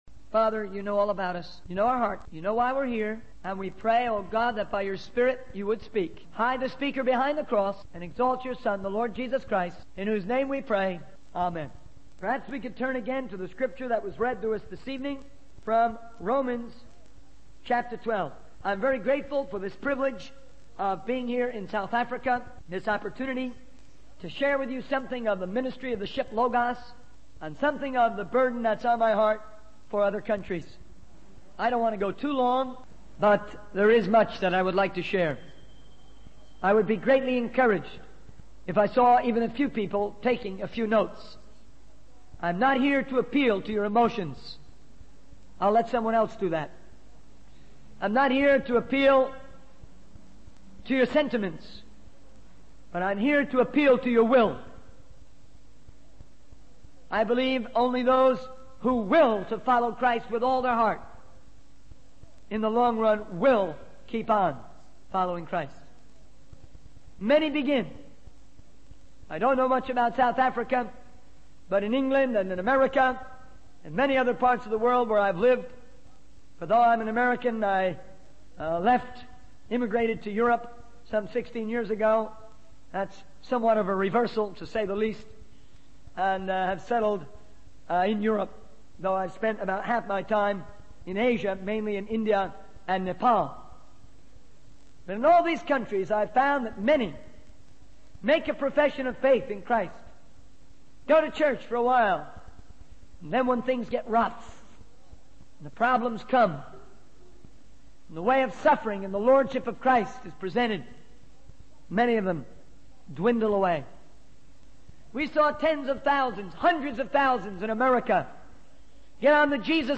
In this sermon, the speaker shares a powerful testimony of how a movement was born from a single woman's prayer. He emphasizes the need for revival in the church and criticizes the lack of passion and fire in many churches.